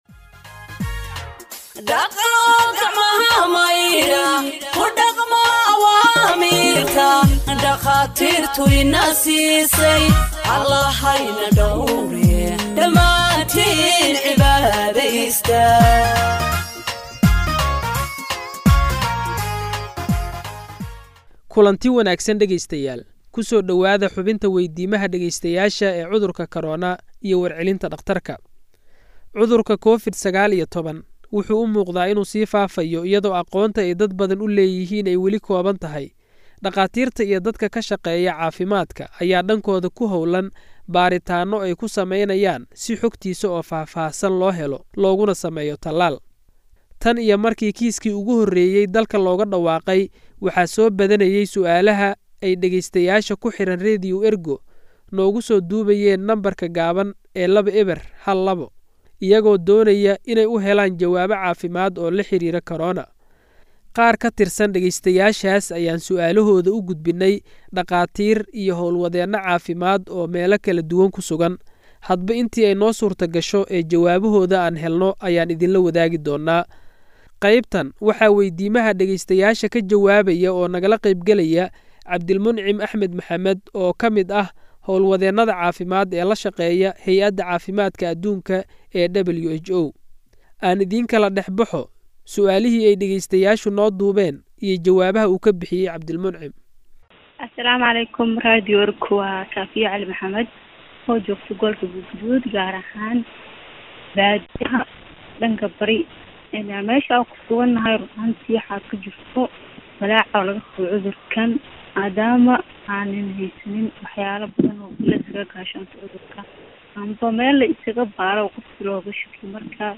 Health expert answers listeners’ questions on COVID 19 (6)
Radio Ergo provides Somali humanitarian news gathered from its correspondents across the country for radio broadcast and website publication.